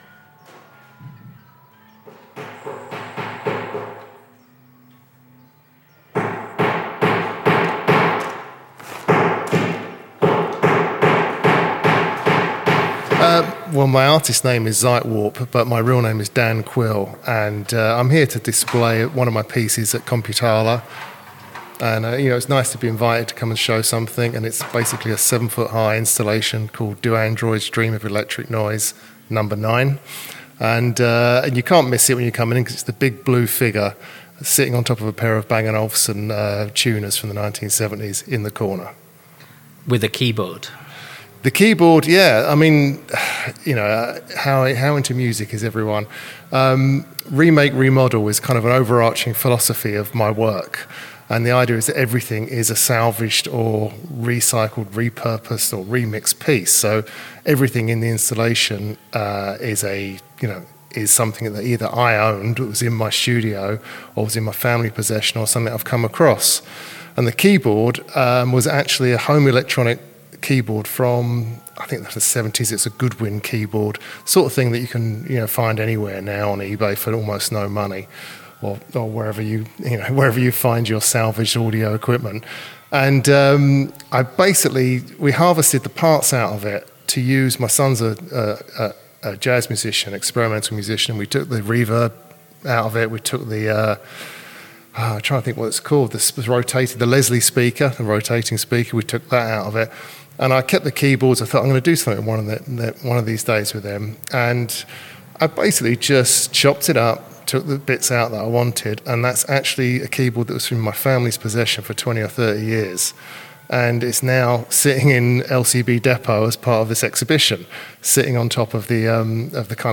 Through a Radio Lear podcast conversation recorded at the launch, artists and curators reflect on how artificial intelligence, robotics, and generative systems are reshaping creative practice. Rather than promoting technology uncritically, the exhibition asks open questions about human agency, authorship, systems, and our relationship with machines.